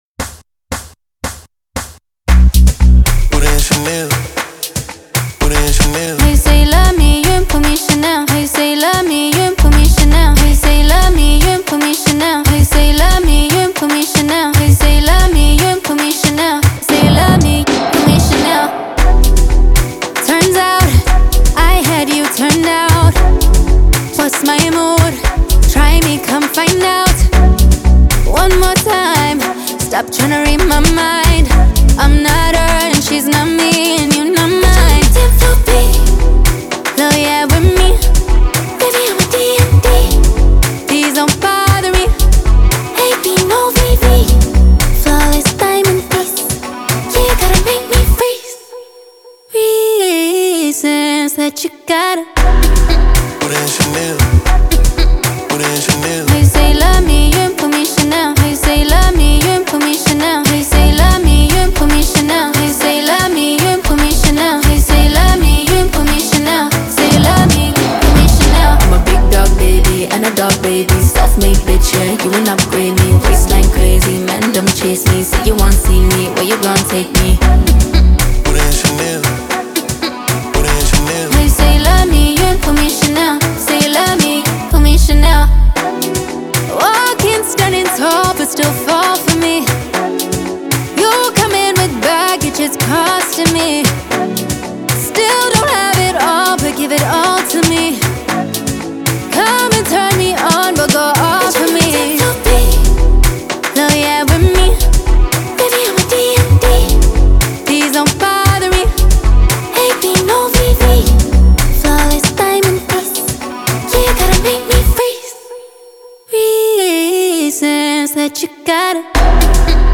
using her honeyed vocals to express themes of self-worth
lyrical delivery feels intimate yet commanding